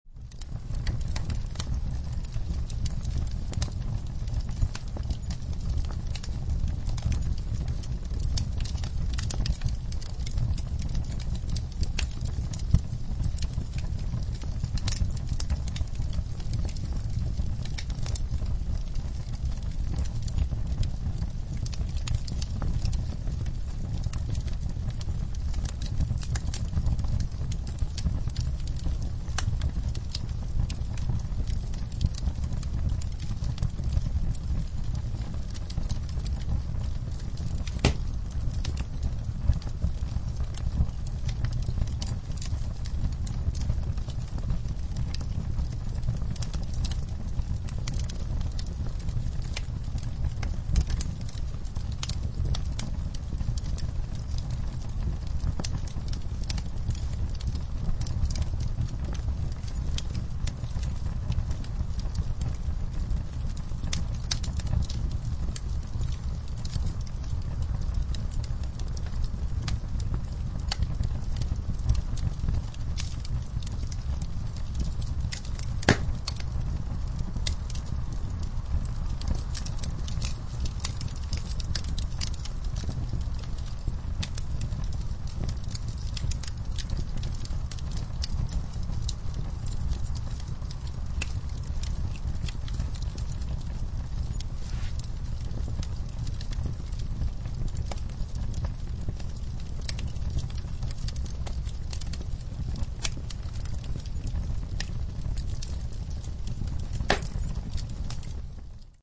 Fireplace.mp3